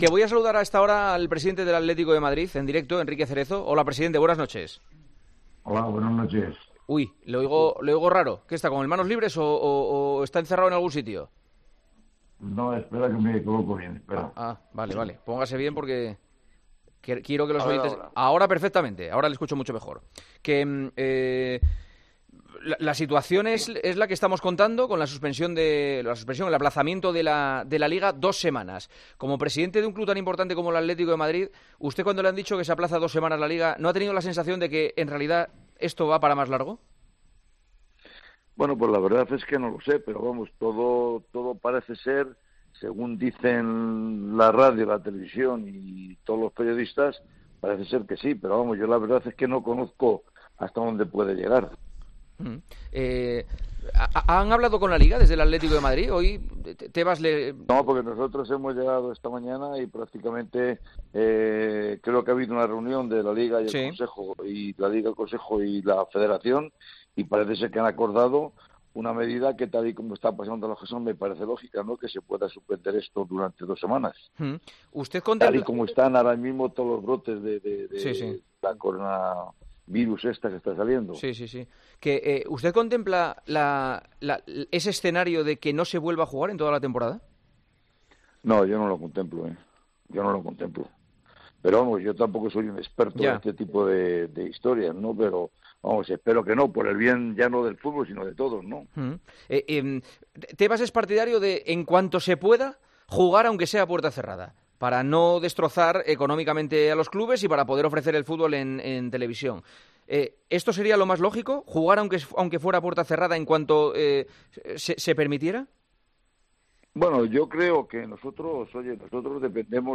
Juanma Castaño charló este jueves en El Partidazo de COPE con el presidente del Atlético de Madrid, el día en que volvieron de Liverpool con una noche histórica, que pasó a segundo plano con la actualidad del coronavirus como protagonista: